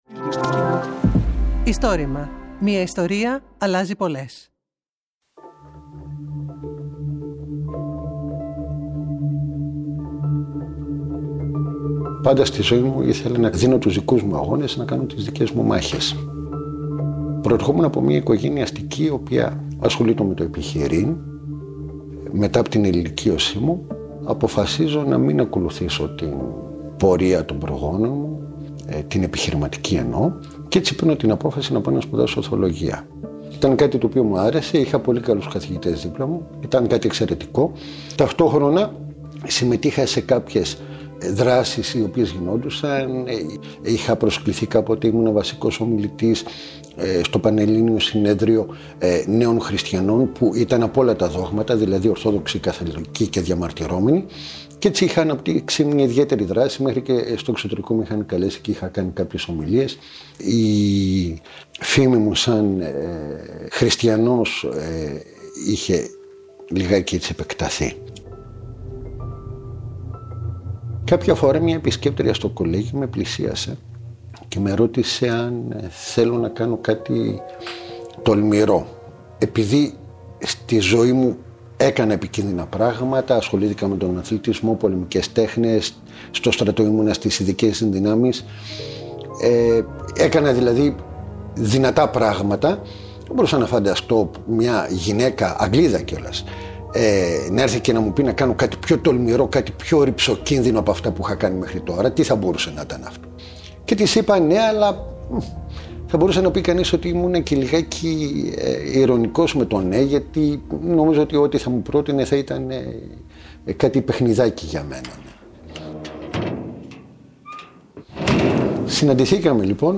Συνέντευξη
Το Istorima είναι το μεγαλύτερο έργο καταγραφής και διάσωσης προφορικών ιστοριών της Ελλάδας.